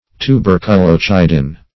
Search Result for " tuberculocidin" : The Collaborative International Dictionary of English v.0.48: Tuberculocidin \Tu*ber"cu*lo*ci`din\, n. [Tuberculum + root of L. caedere to kill.]
tuberculocidin.mp3